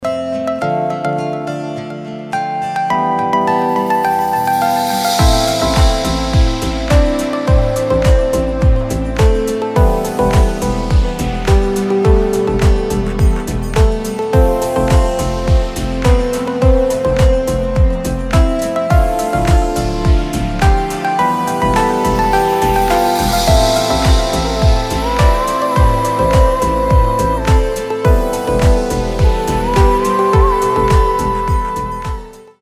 • Качество: 320, Stereo
deep house
мелодичные
спокойные
без слов
восточные
Восточный deep house